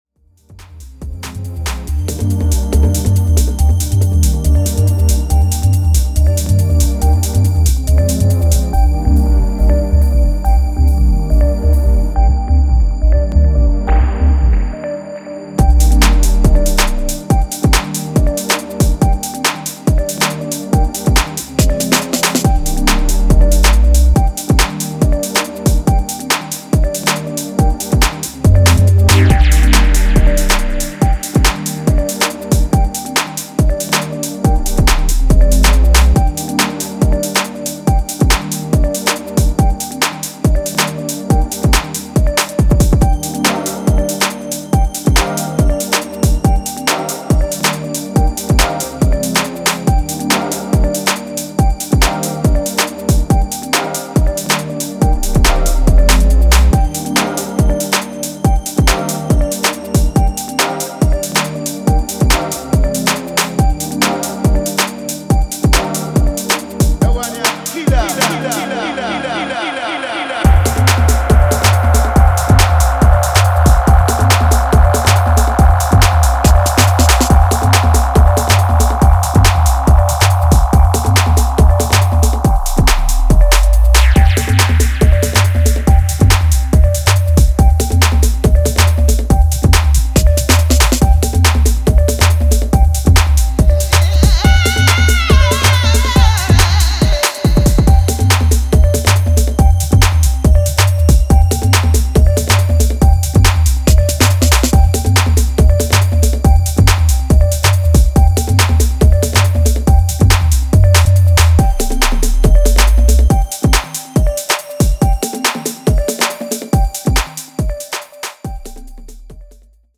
うねるサブと軽快にロールするパーカッションで足を止めさせない140ステッパーズ